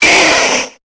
Cri de Caninos dans Pokémon Épée et Bouclier.